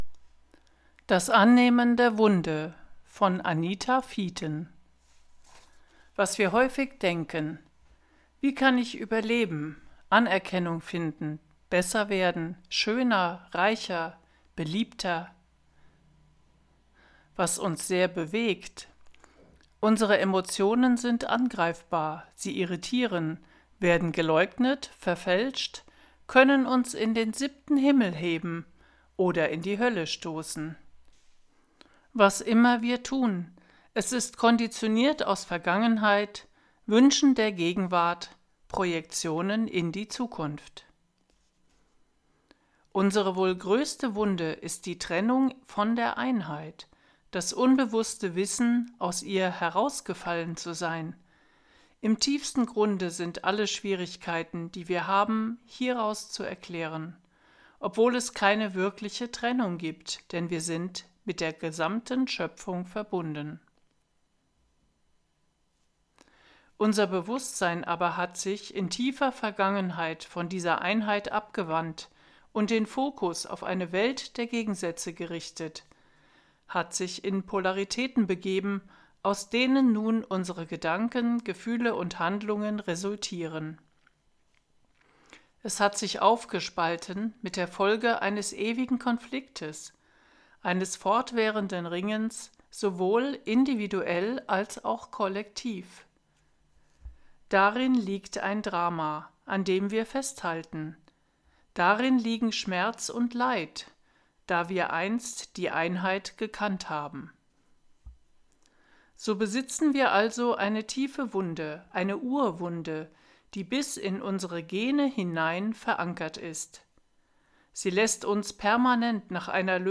Artikel vorgelesen